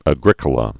(ə-grĭkə-lə), Gnaeus Julius AD 40-93.